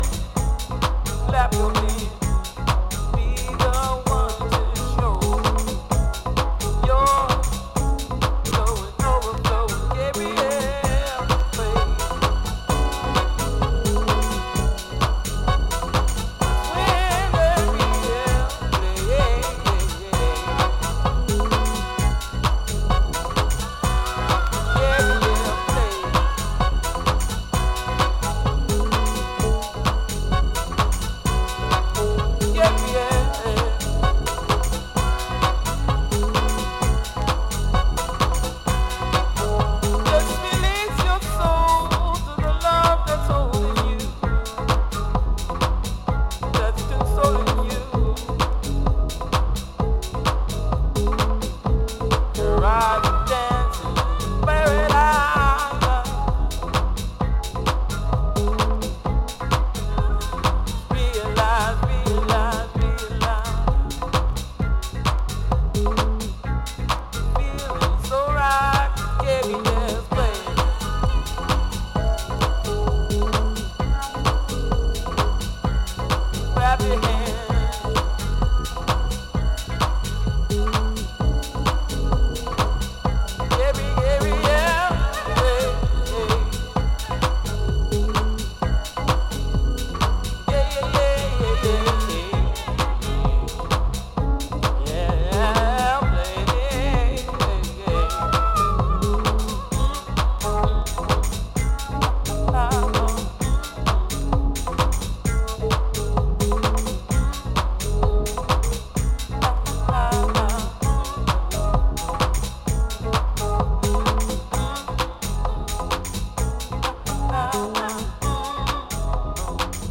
ディープで洗練されたソウル/ジャズ感を含んだ傑作ハウス作品です。